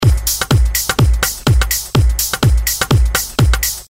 描述：鼓，鼓循环，鼓循环，房子，房子循环
Tag: 125 bpm House Loops Drum Loops 662.05 KB wav Key : Unknown